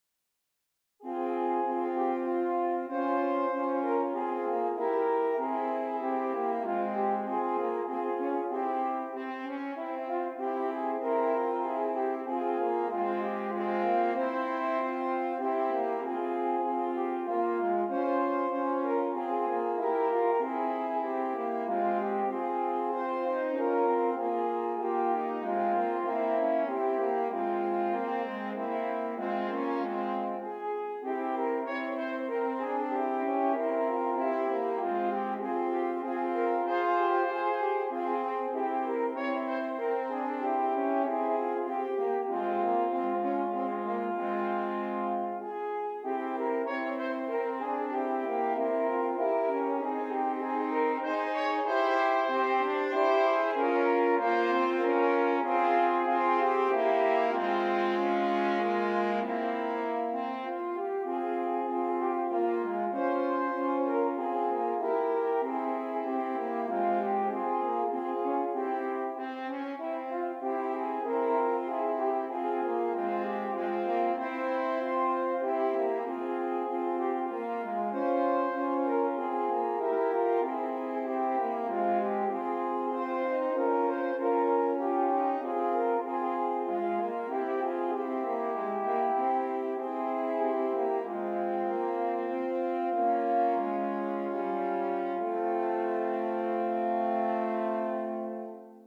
3 F Horns